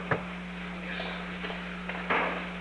Secret White House Tapes
Conversation No. 532-2
Location: Oval Office